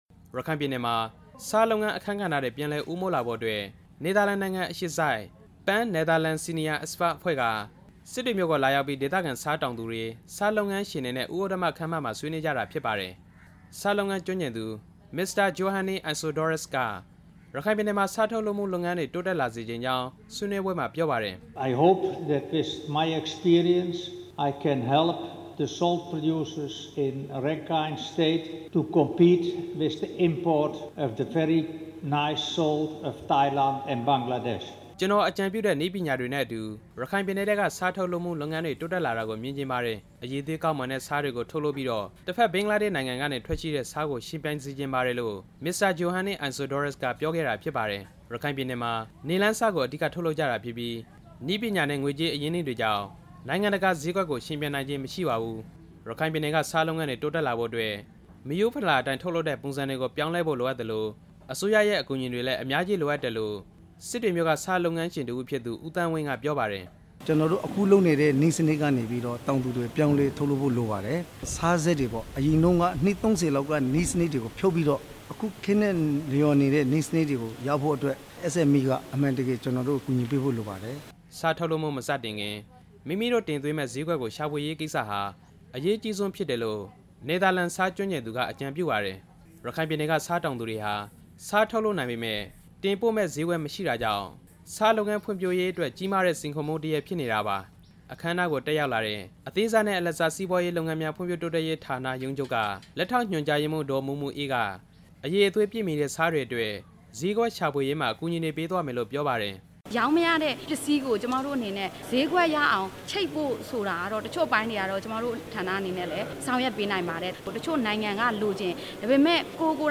ဆားတောင်သူတွေရဲ့ အခက်အခဲတွေအကြောင်း တင်ပြချက်